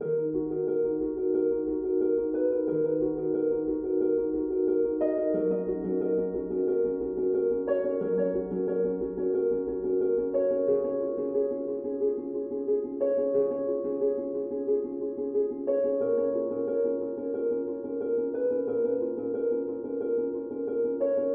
标签： 90 bpm Acoustic Loops Harp Loops 3.59 MB wav Key : Unknown FL Studio